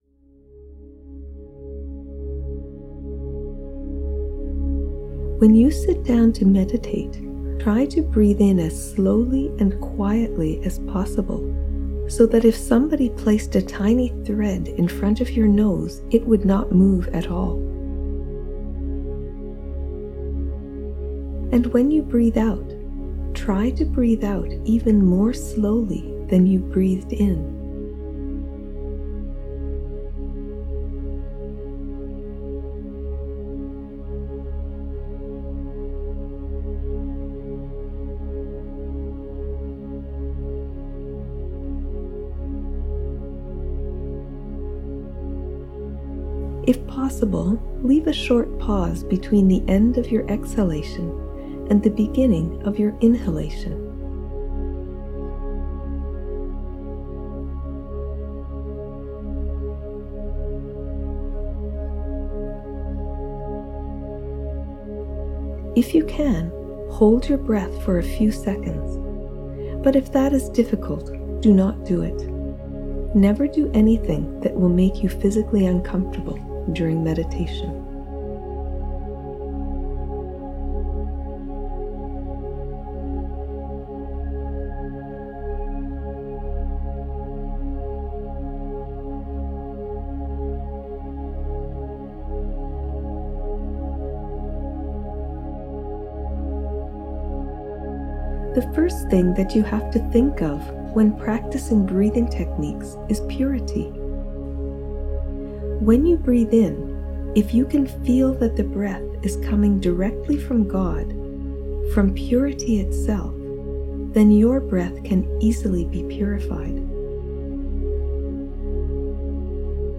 VOĐENE MEDITACIJE 2 (eng)